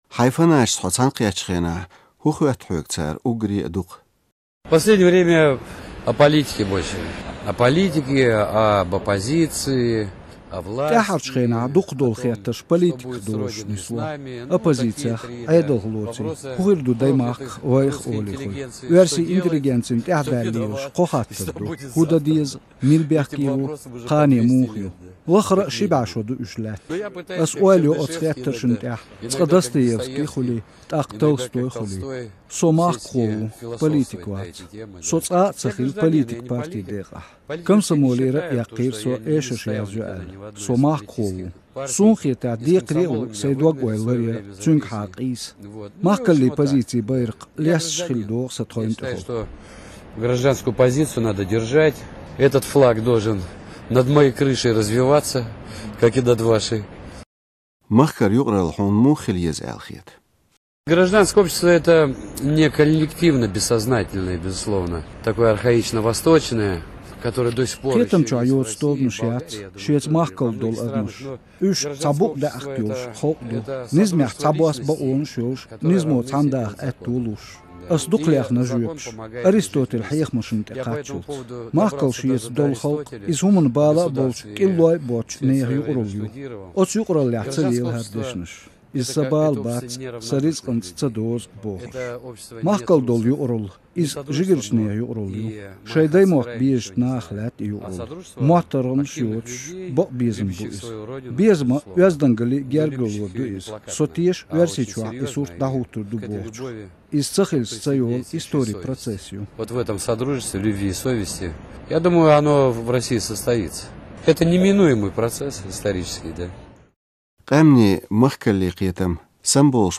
Шевчук Юрийца къамел